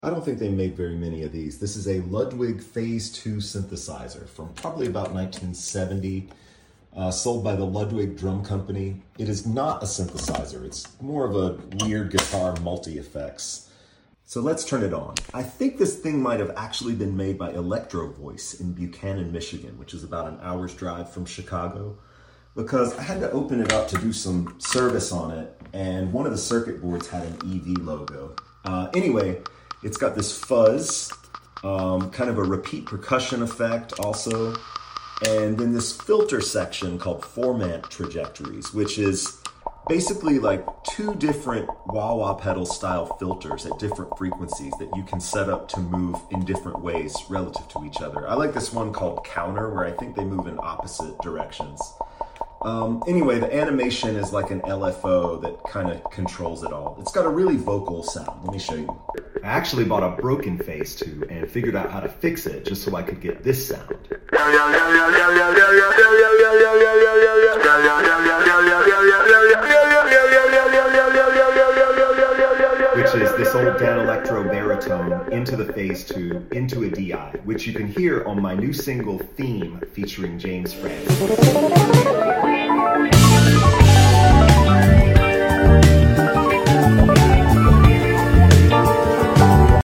gtr fx
turns out it’s one of the strangest, tweakiest pieces of music tech i’ve ever encountered . the window where it sounds amazing is hilariously tiny + sits on a thin line between two different kinds of “doesn’t work” .